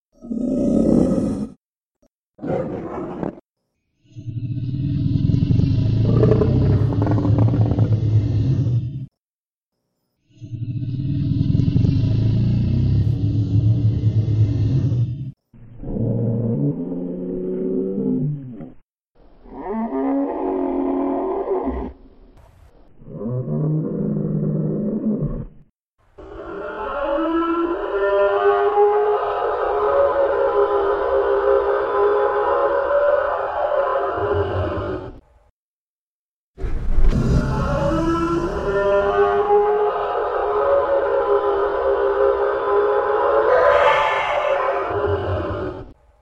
T-rex Calls
T-rex growling, huffing, bellowing, and roaring.
t-rex_calls.mp3